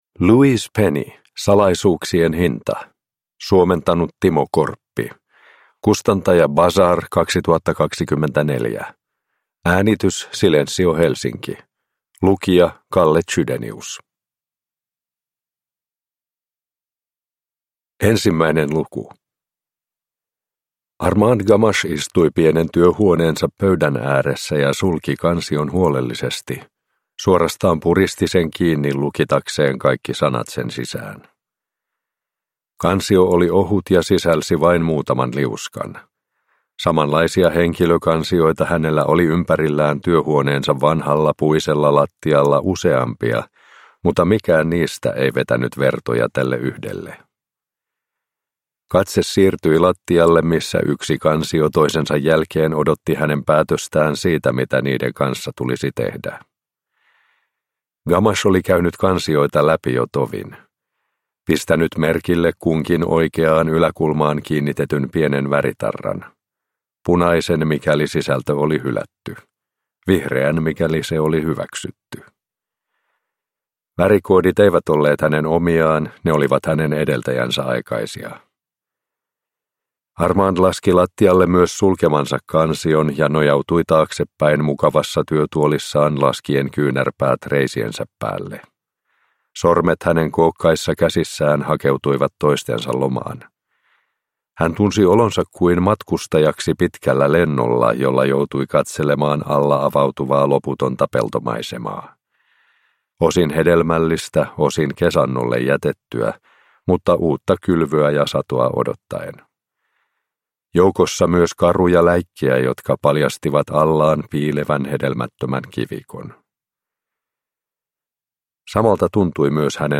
Salaisuuksien hinta – Ljudbok